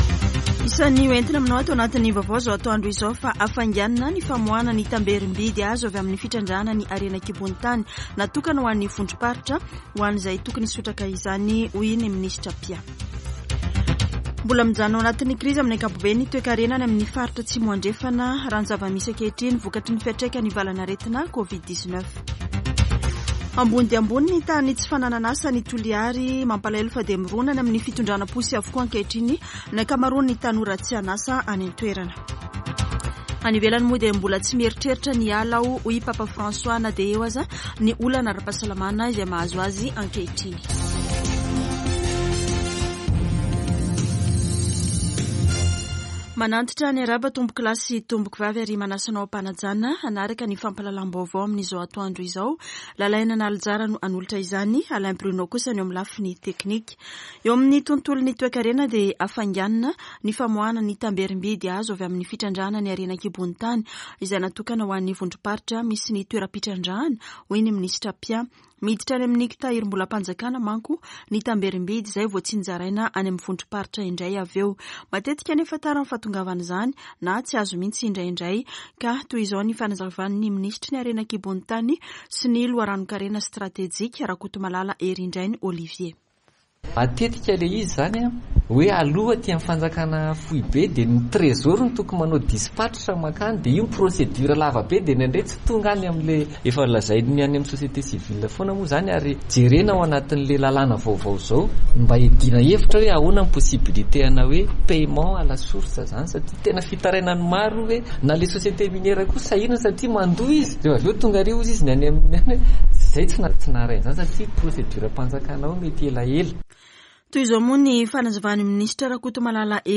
[Vaovao antoandro] Alarobia 13 jolay 2022